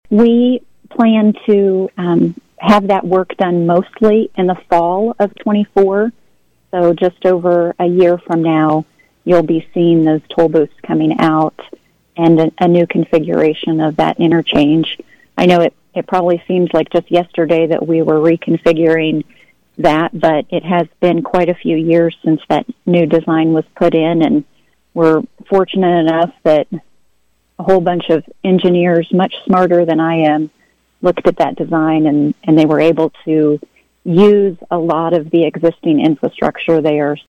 Emporia, KS, USA / KVOE